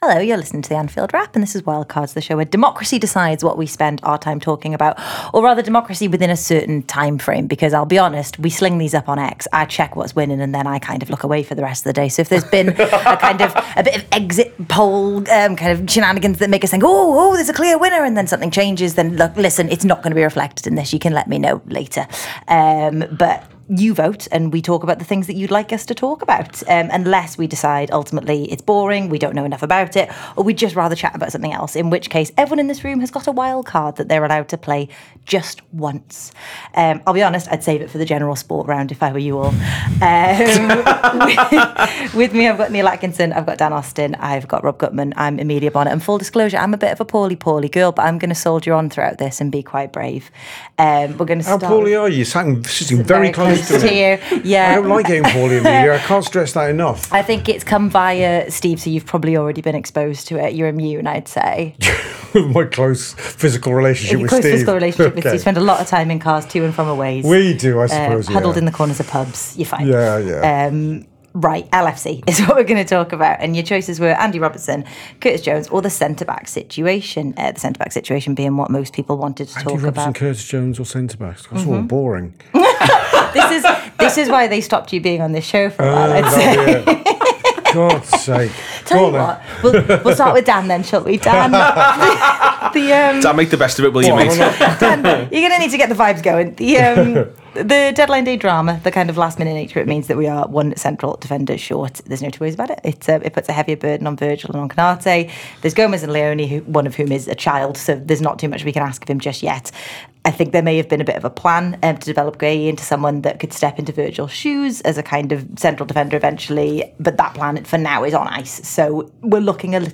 Below is a clip from the show – subscribe for more on the popular topics of the week…